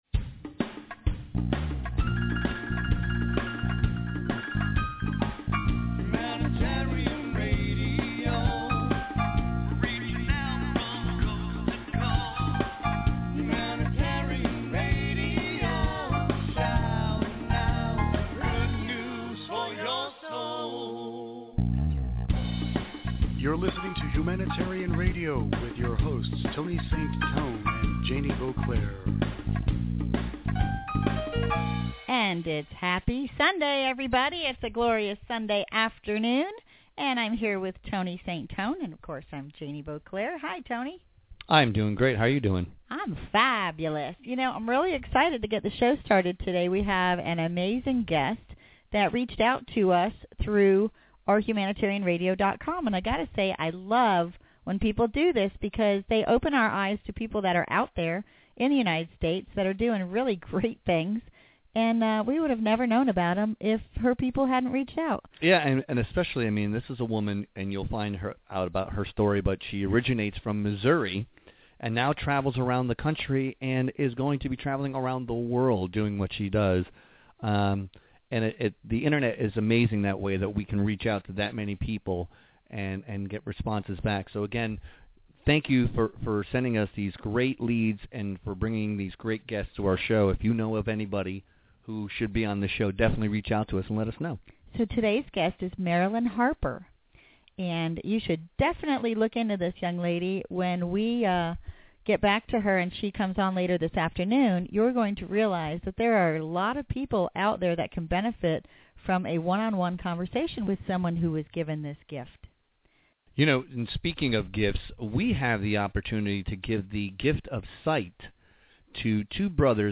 HR Interview